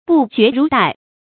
不絕如帶 注音： ㄅㄨˋ ㄐㄩㄝˊ ㄖㄨˊ ㄉㄞˋ 讀音讀法： 意思解釋： 形容局勢危急，象差點兒就要斷掉的帶子一樣。